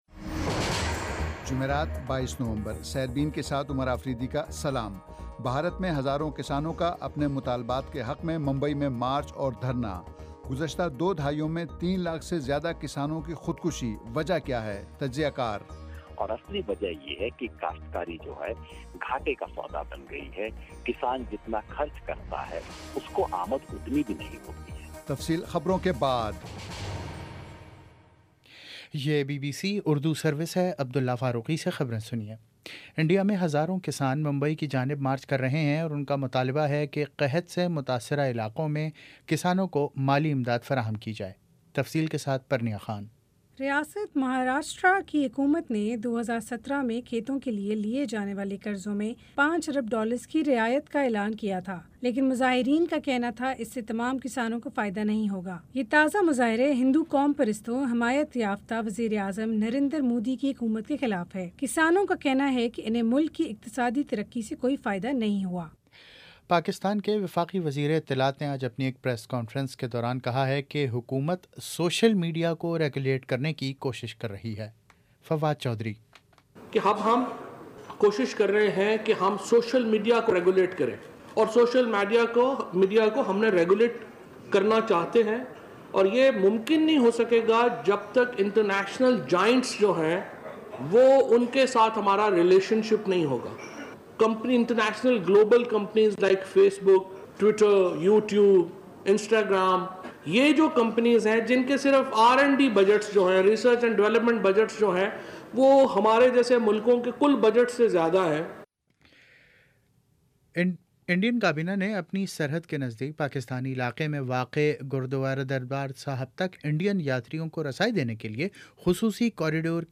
جمعرات 22 نومبر کا سیربین ریڈیو پروگرام